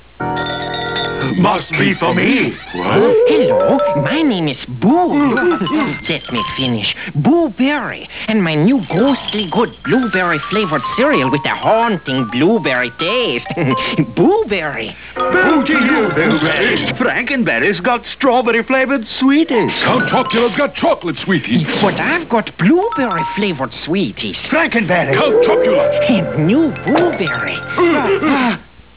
Download the Boo Berry Commercial Soundclip! (226k)
booberry-commercial.au